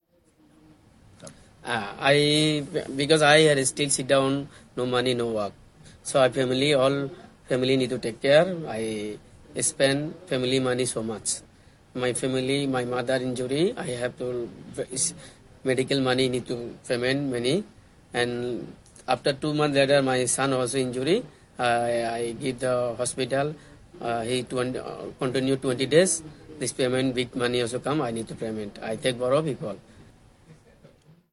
Here are some extracts from the debriefing interview.